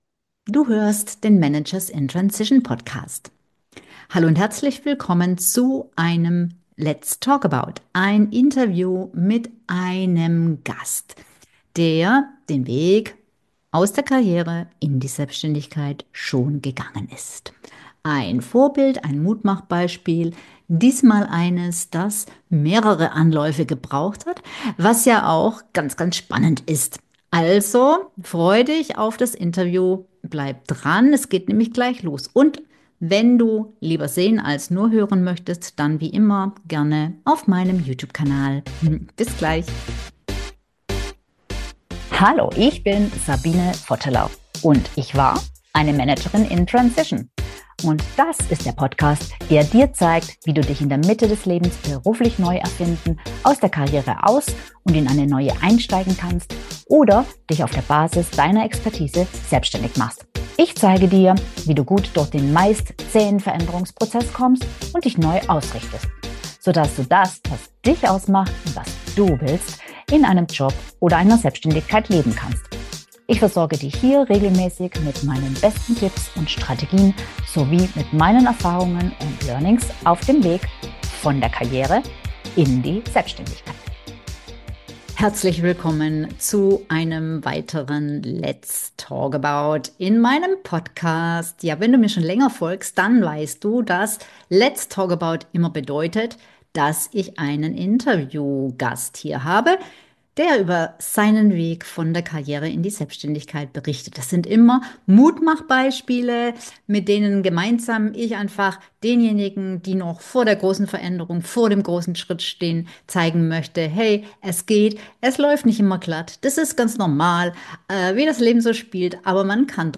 182: In dieser Podcast-Episode sprechen mein Interview-Gast und ich über die verschlungenen Pfade seines Wegs in die Selbstständigkeit.